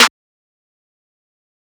Metro Snares [Drive Shootin].wav